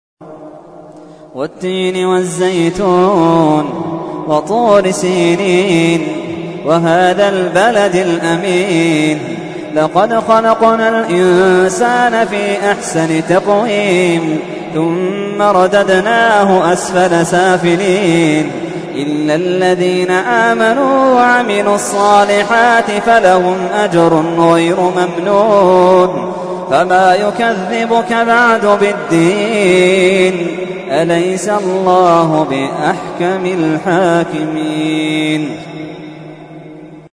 تحميل : 95. سورة التين / القارئ محمد اللحيدان / القرآن الكريم / موقع يا حسين